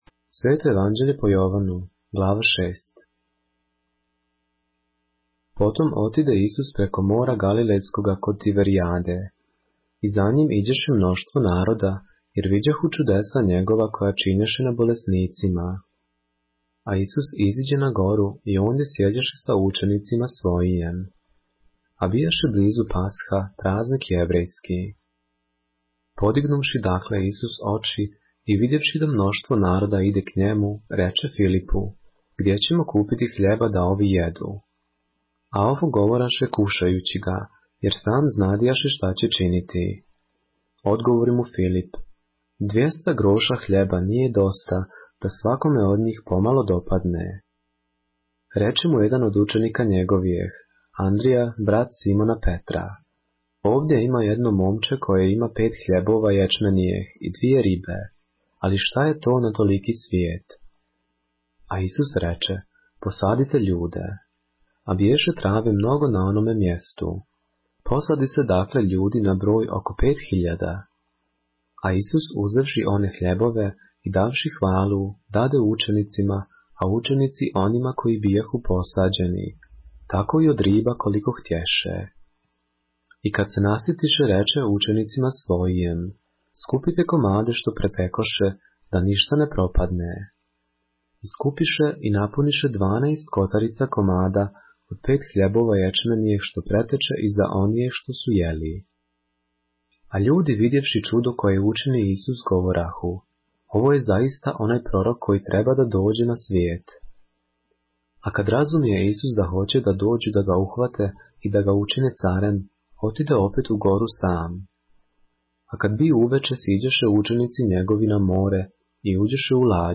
поглавље српске Библије - са аудио нарације - John, chapter 6 of the Holy Bible in the Serbian language